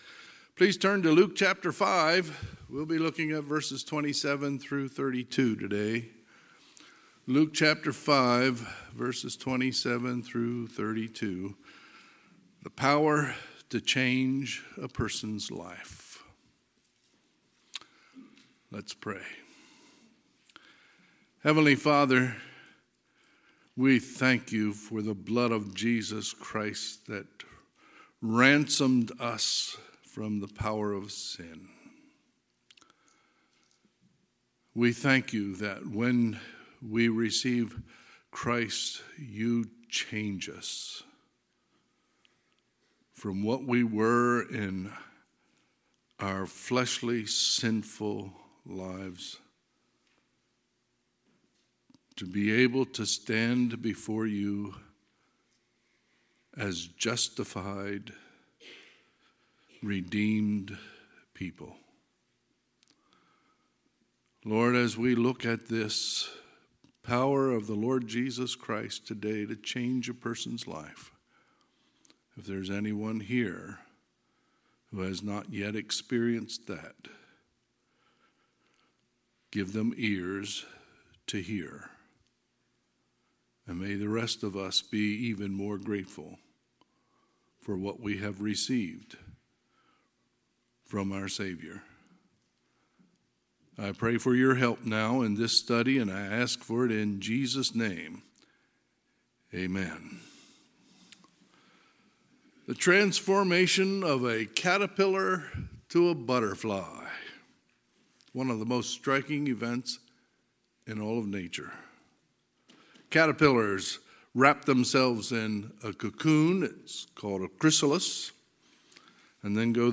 Sermons | North Hills Bible Church | Page 22